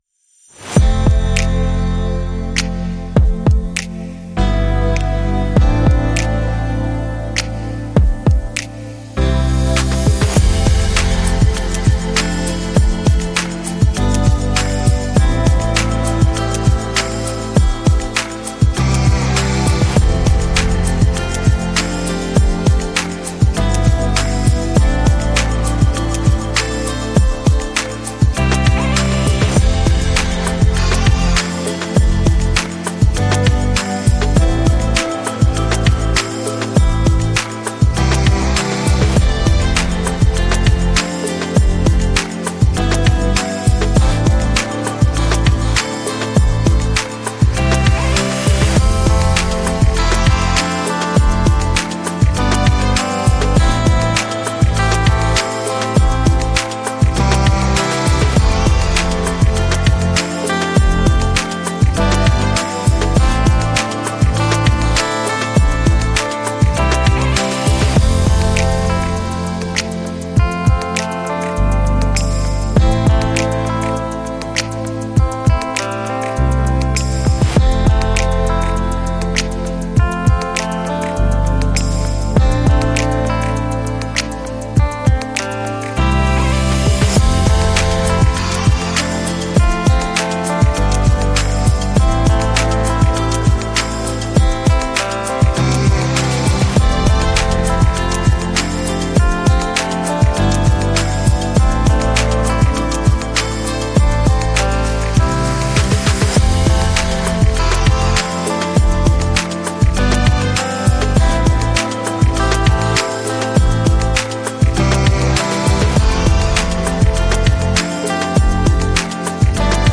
8 - Relax Chillout Atmosphere